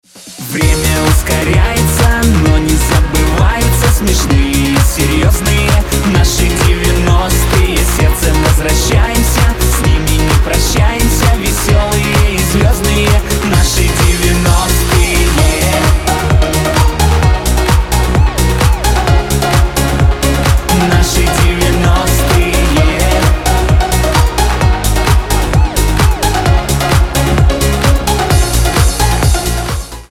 мужской голос
ностальгия